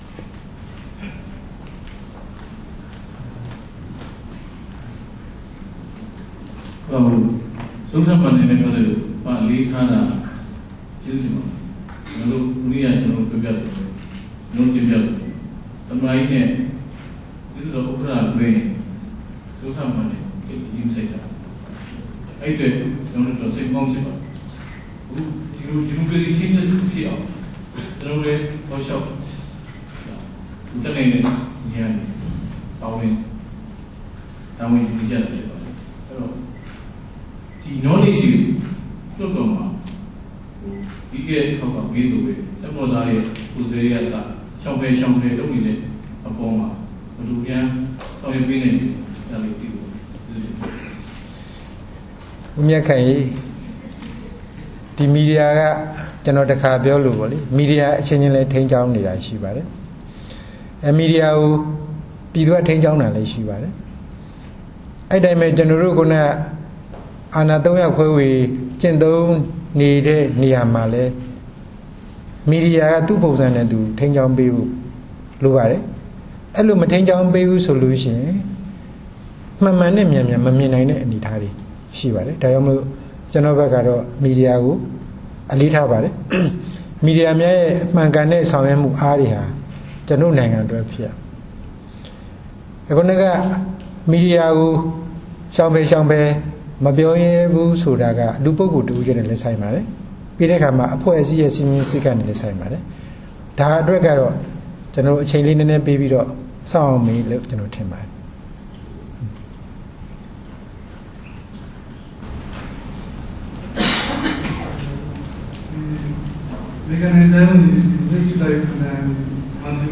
ပြည်သူ့လွှတ်တော် ဥက္ကဋ္ဌ သူရ ဦးရွှေမန်း သတင်းစာ ရှင်းလင်း